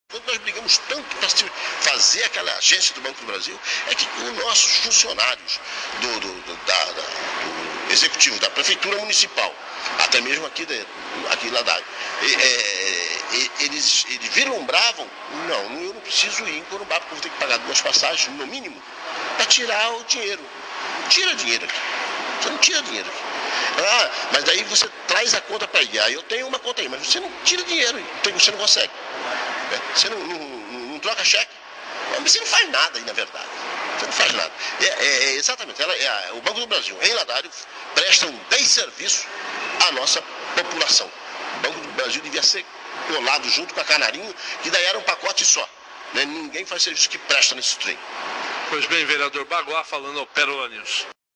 Entrevista com o vereador Baguá
ENTREVISTA NA ÍNTEGRA – PARTE 02